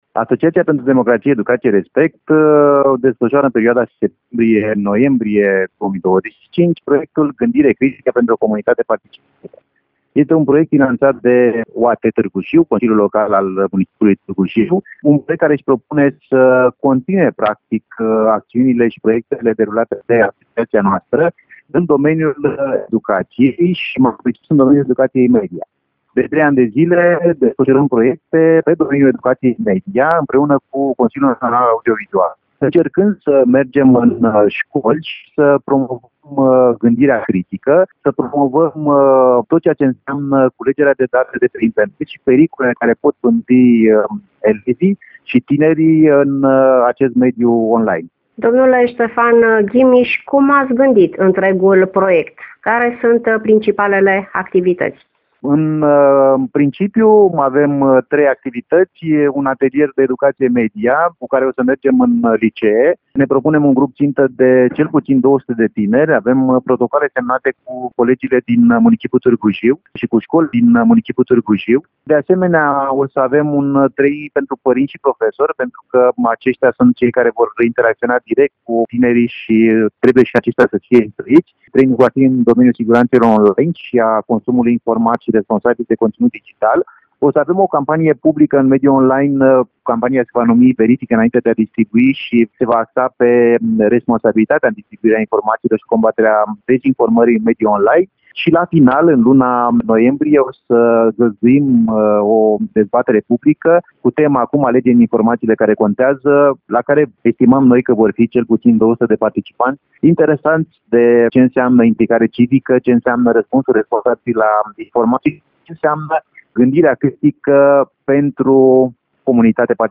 Detalii in interviul